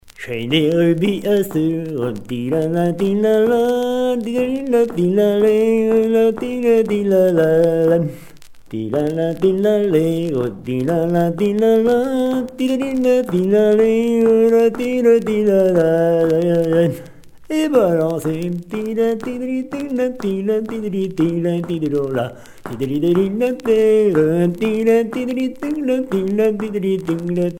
Mémoires et Patrimoines vivants - RaddO est une base de données d'archives iconographiques et sonores.
Quadrille de Candé turluté - Chaîne des dames